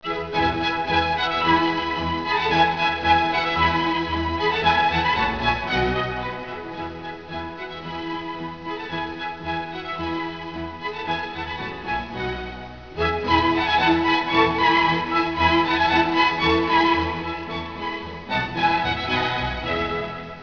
* The Four Seasons (Le quattro stagioni in original Italian) is a set of four violin concertos by Antonio Vivaldi.
Composed in 1723, it is Vivaldi's best-known work, and is among the most popular pieces of Baroque music.